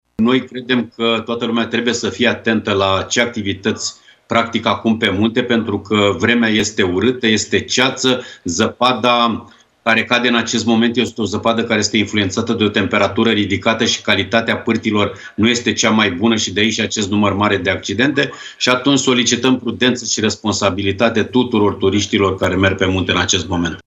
Într-o declarație pentru Digi24